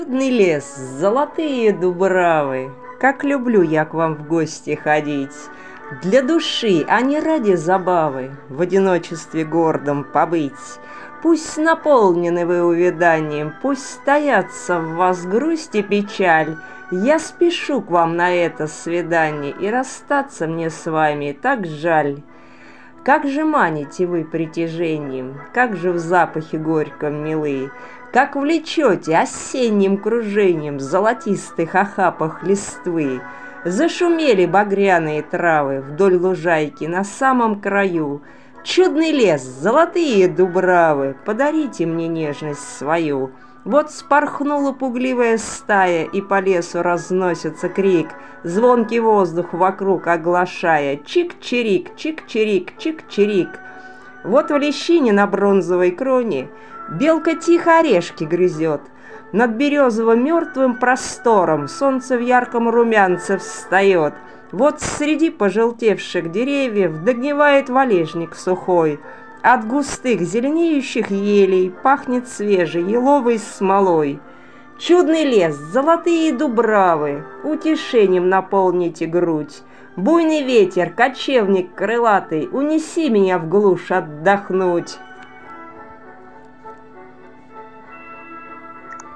Музыка классики Озвучка автора